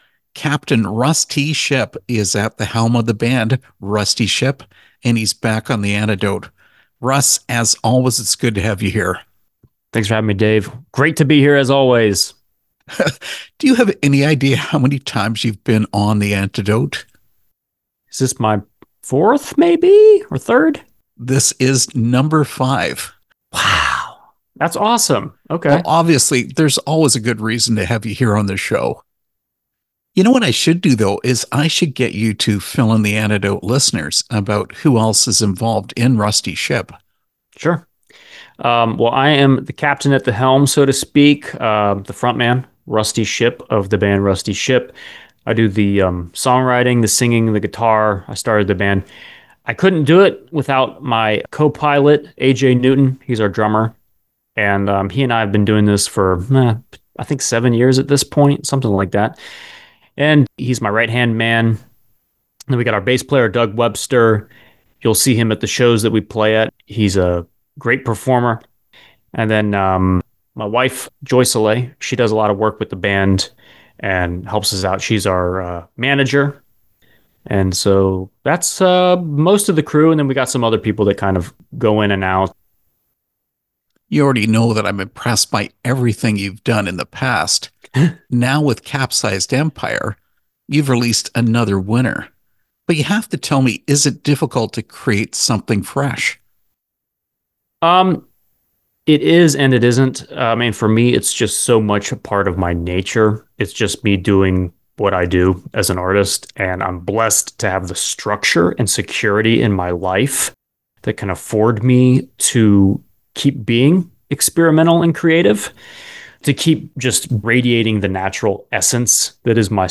Interview with Rusty Shipp (2025)